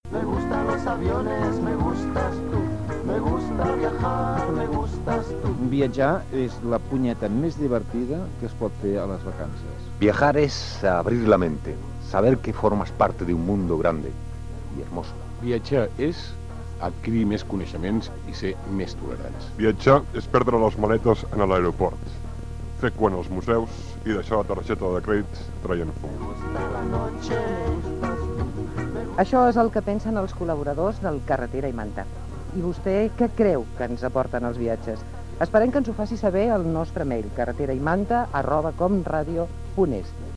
Promoció del programa basada en el fet de viatjar